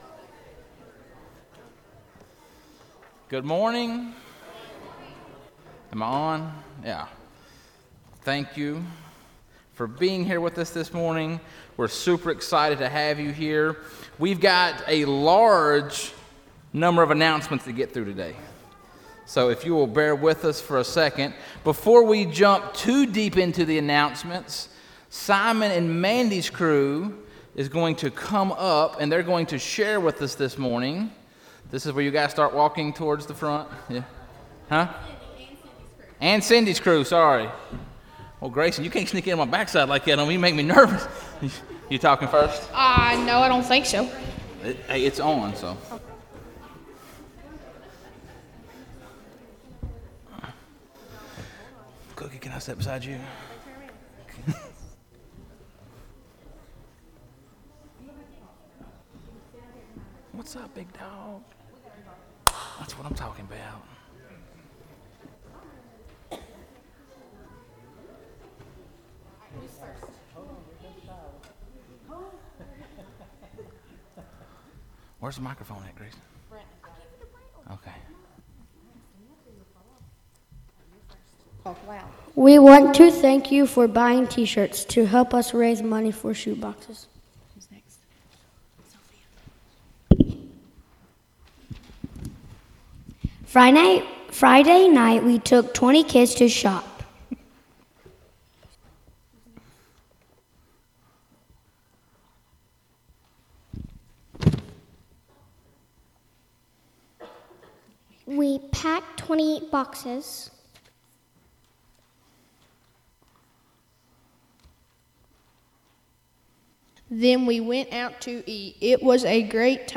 Sunday, November 7, 2021 (Sunday Morning Service)